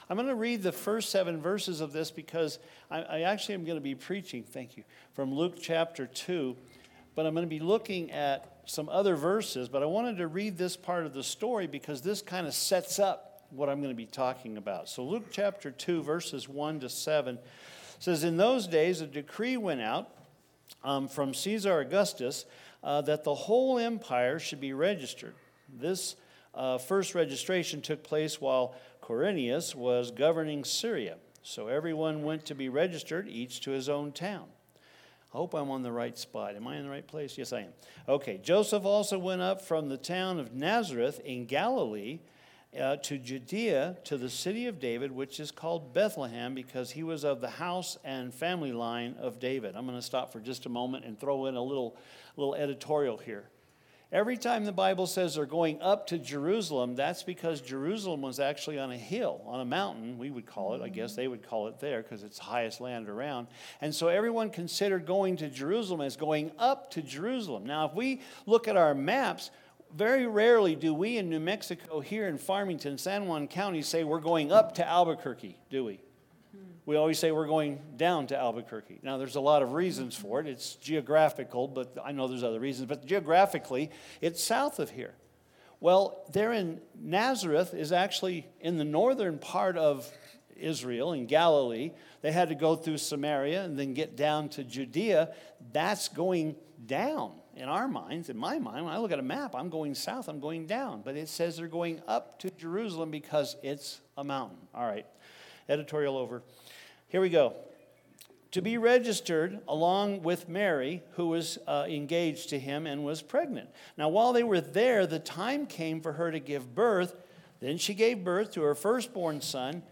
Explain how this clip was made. Christmas Eve Service - Joyfully Celebrating His Birth